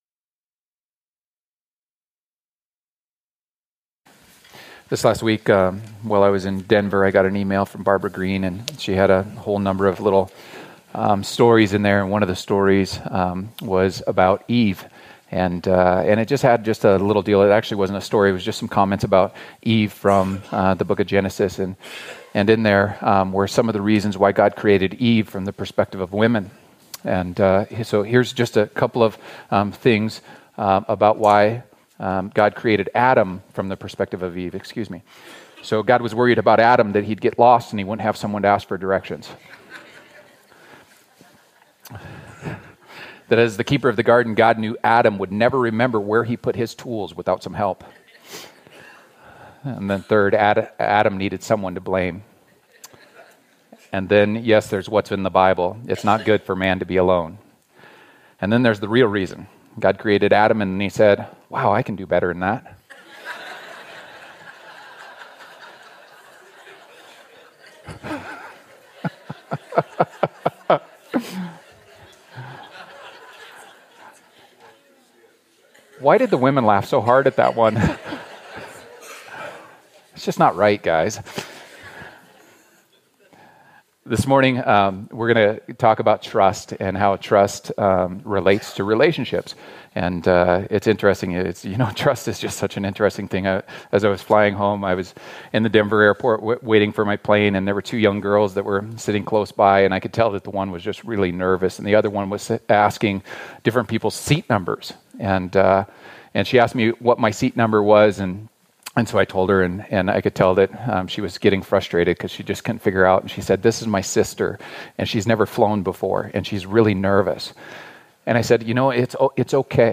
A message from the series "Relate."